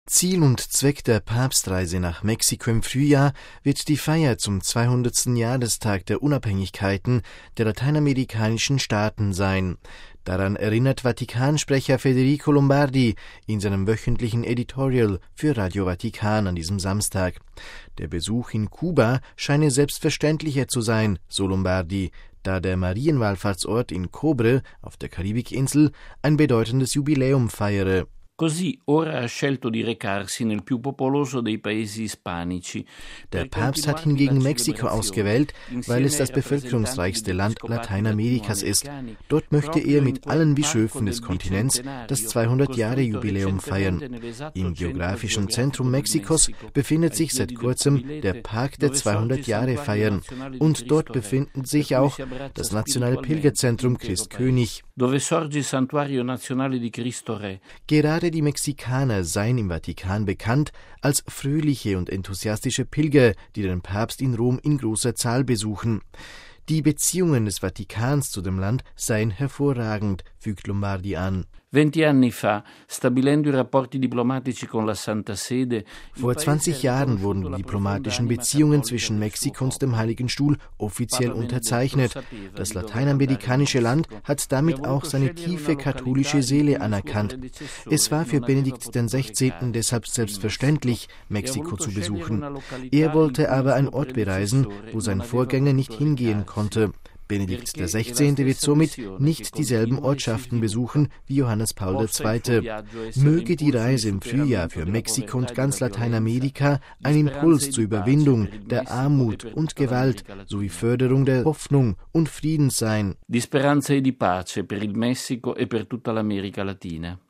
MP3 Ziel und Zweck der Papstreise nach Mexiko im Frühjahr wird die Feier zum 200. Jahrestag der Unabhängigkeiten der lateinamerikanischen Staaten sein. Daran erinnert Vatikansprecher Federico Lombardi in seinem wöchentlichen Editorial für Radio Vatikan an diesem Samstag.